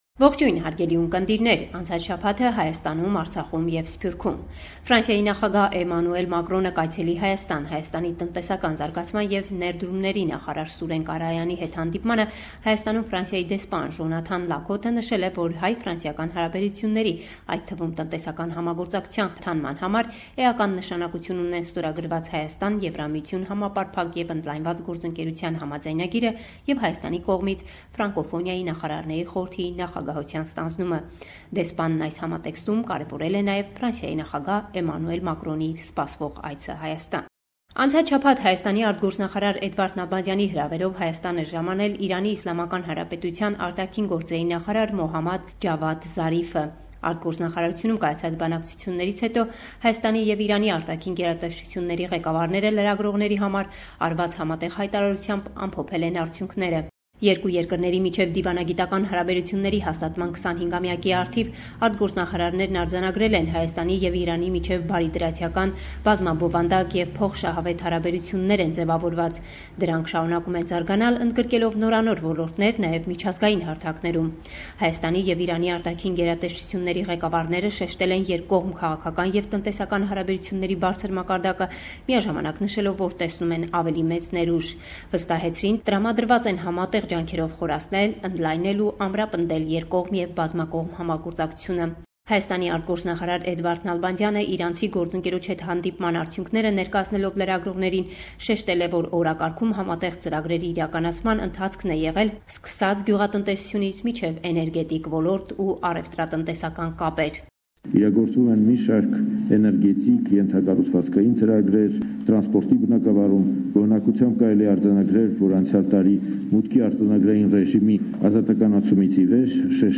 Հայաստանեան եւ հայութեան հետ առնչութիւն ունեցող վերջին իրադարձութիւններու մասին լուրերը կը հաղորդէ մեր թղթակիցը